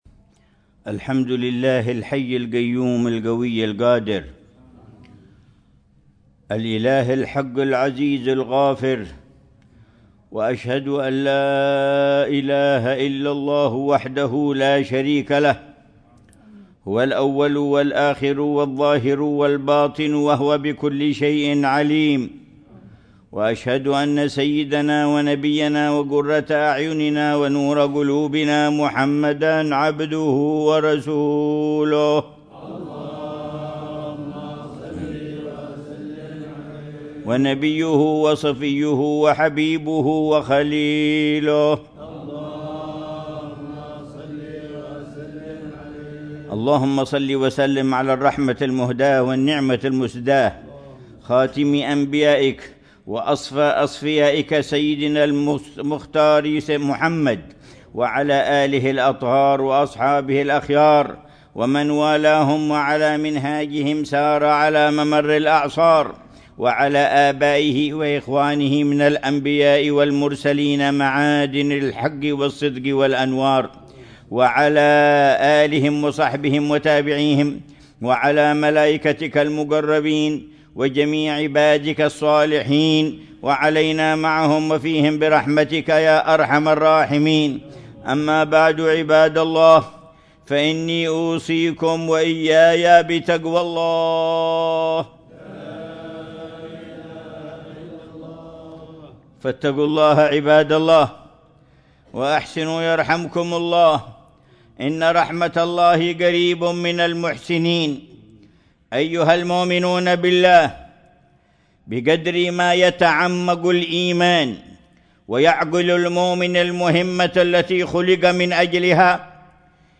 خطبة الجمعة للعلامة الحبيب عمر بن محمد بن حفيظ في جامع الشيخ أبي بكر في منطقة عينات، بوادي حضرموت، 28 جمادى الآخرة 1447هـ بعنوان: